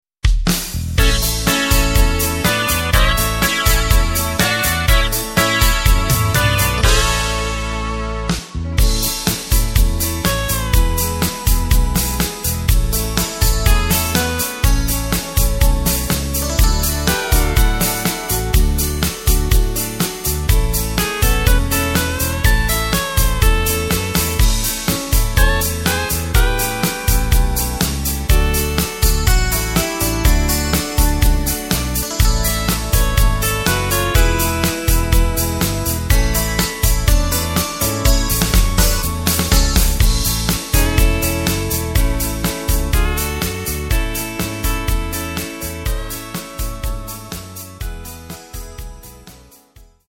Tempo:         123.00
Tonart:            A
Country-Beat aus dem Jahr 2010!
Playback mp3 Demo